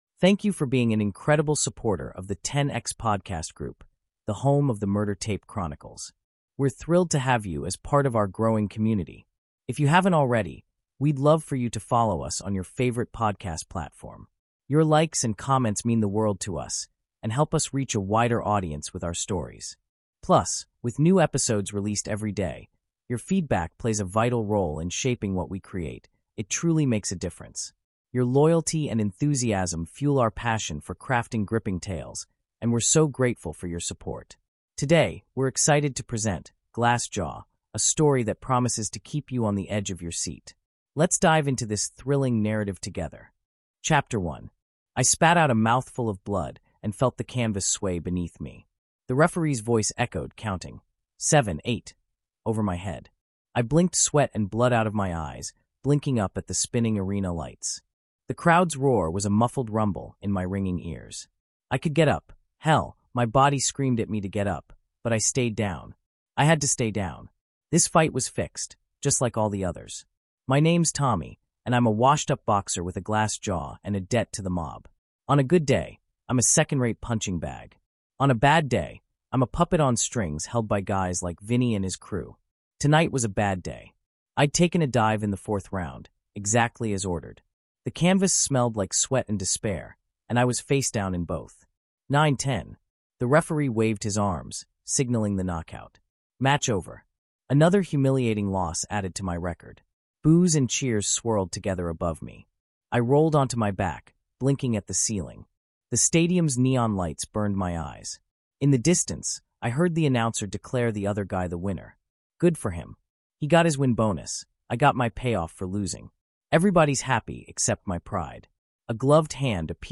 Glass Jaw | Audiobook